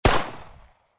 Enemy_attack.mp3